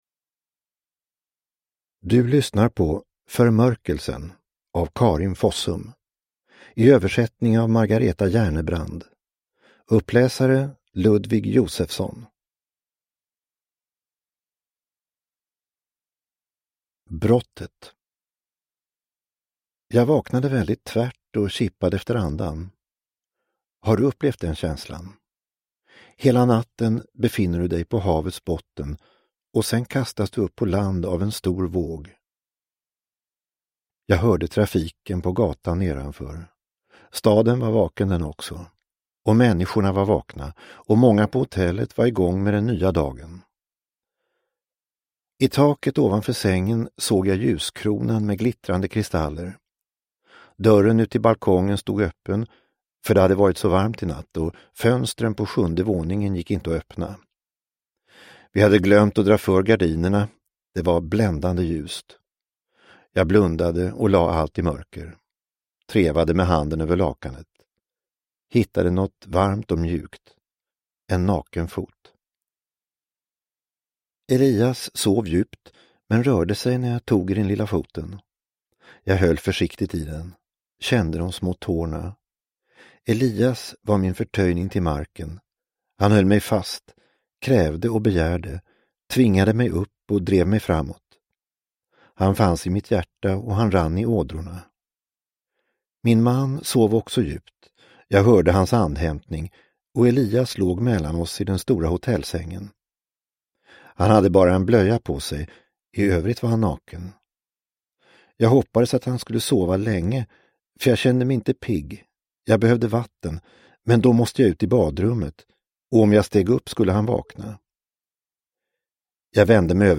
Förmörkelsen – Ljudbok – Laddas ner